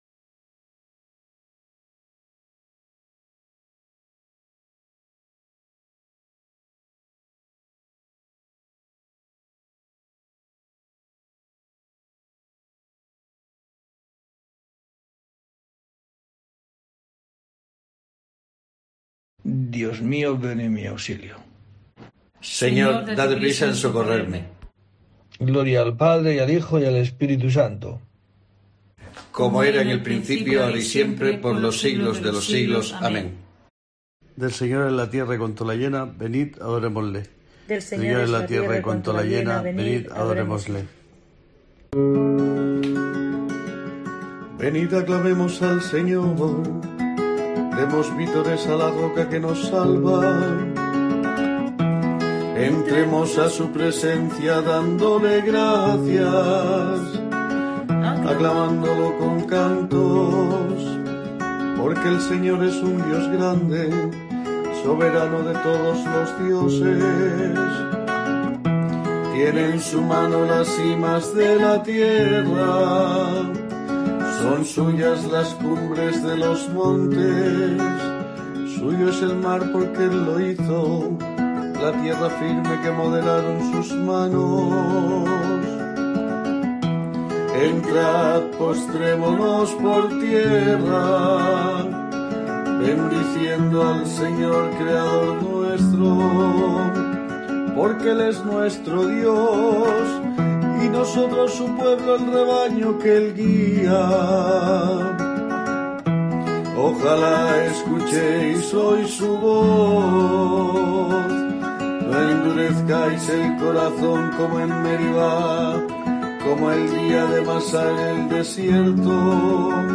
En este día tiene lugar una nueva oración en el rezo de Laudes.